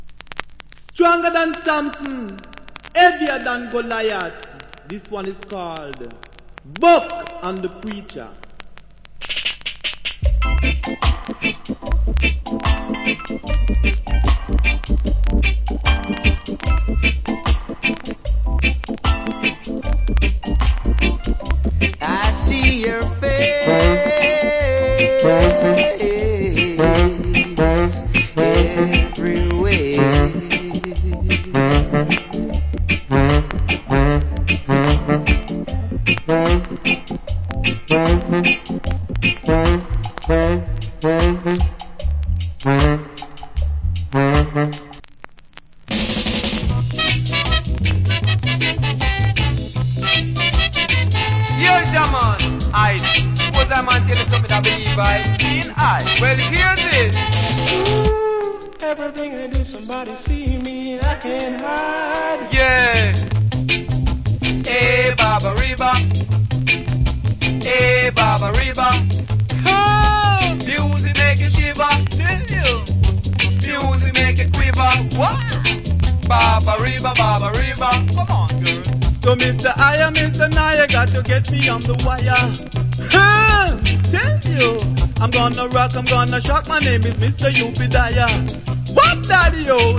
Cool Inst.